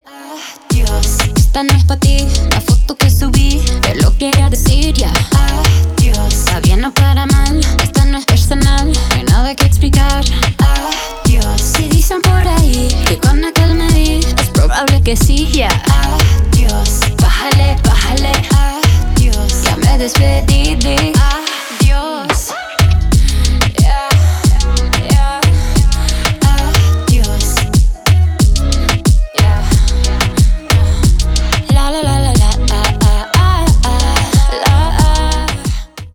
Поп Музыка
клубные # латинские